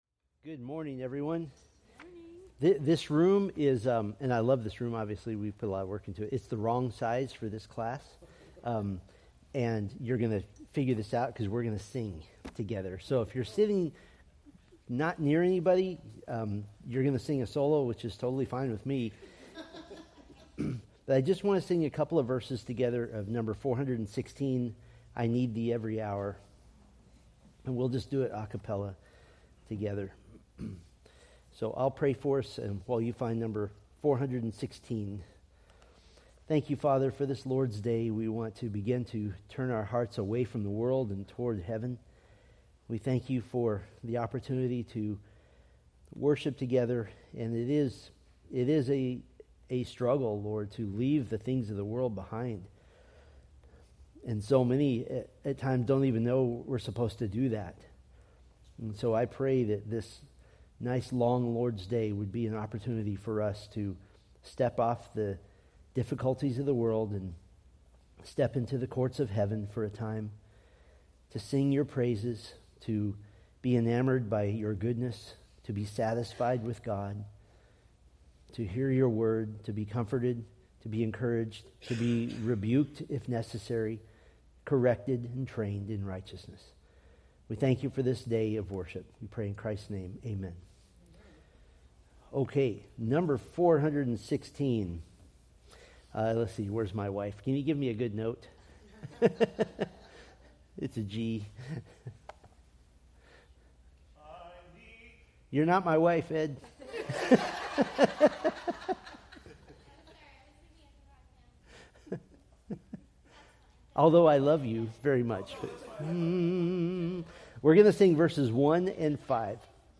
Date: Oct 6, 2024 Series: Psalms Grouping: Sunday School (Adult) More: Download MP3